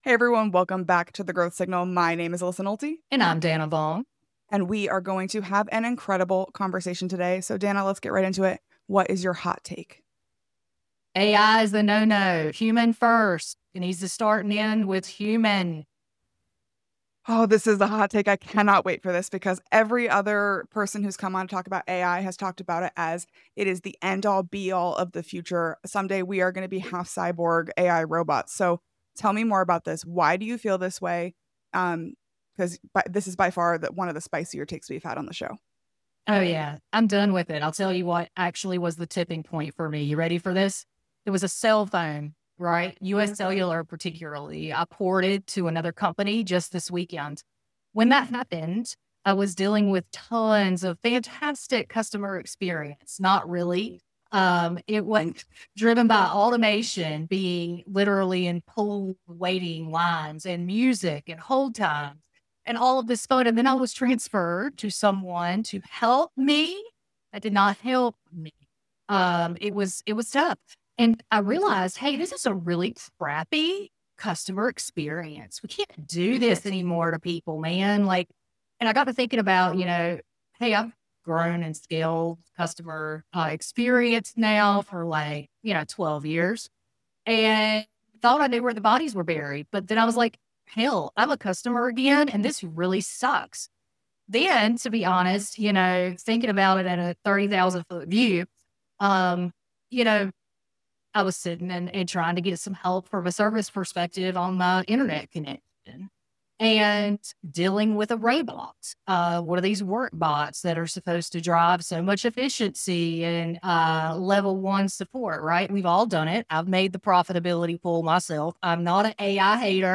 each episode features honest, unscripted conversations with leaders in sales, customer success, marketing, and growth.